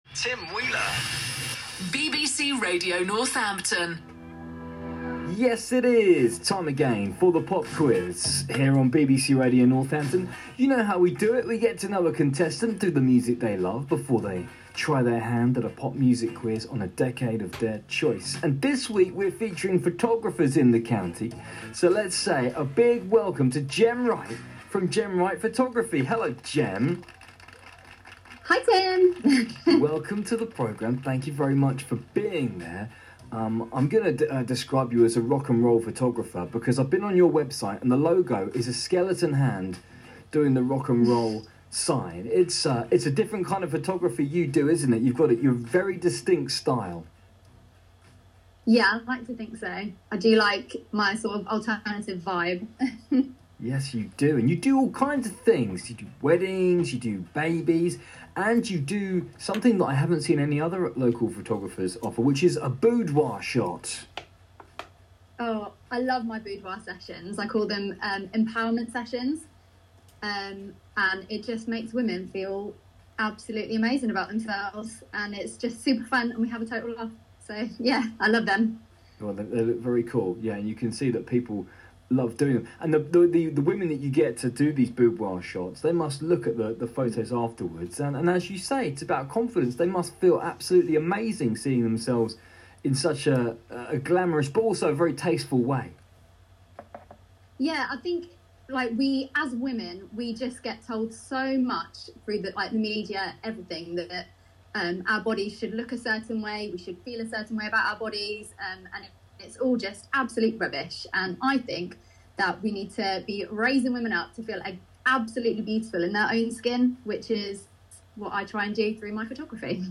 I was contacted by the local radio station to have a chat and do a pop quiz. I was absolutely bricking it but luckily he was a lovely chap and even turned the ‘pop’ quiz into a rock quiz 🤘🏻
Listening back, I can tell how nervous I was – I always get super awkward when the attention is on me! I do this weird thing when I get nervous and I sort of go a bit hyper.